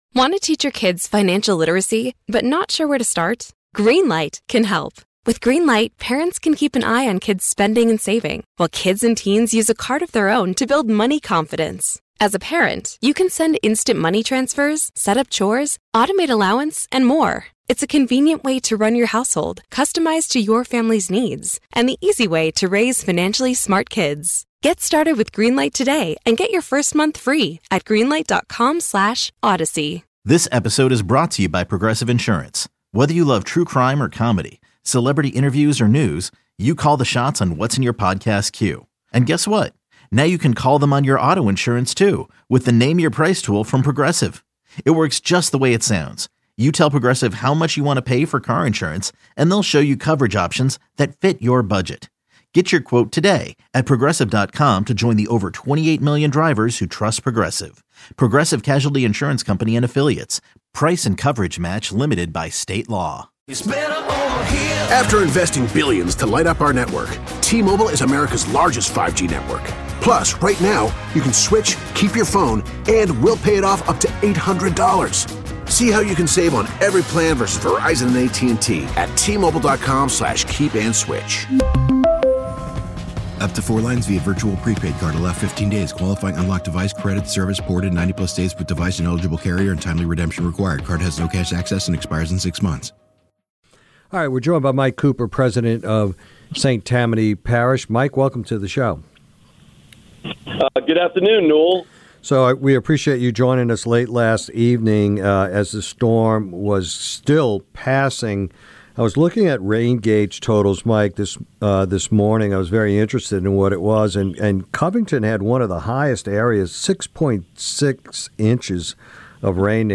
Mike Cooper, the President of St. Tammany Parish, joined Newell to discuss the aftermath of Hurricane Francine.